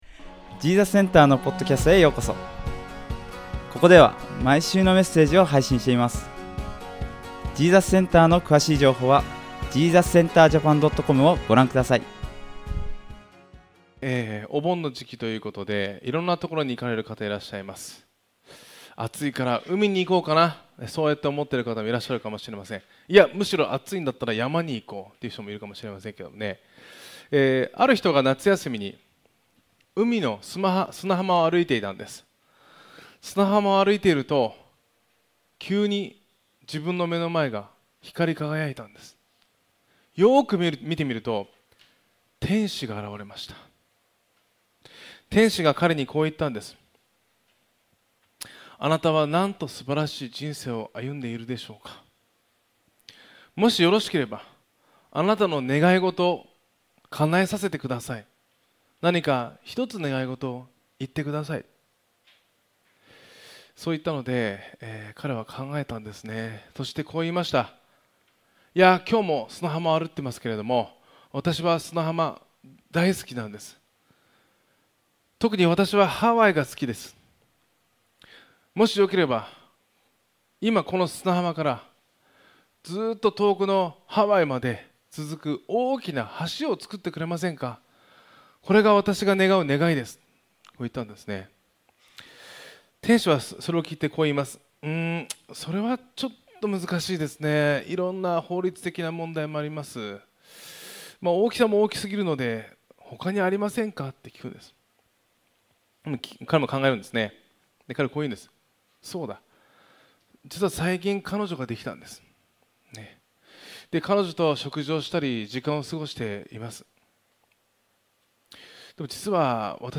２ペテロ２：１〜２ 1 イエス・キリストのしもべであり使徒であるシモン・ペテロから、私たちの神であり ジーザスセンターの聖書のメッセージです。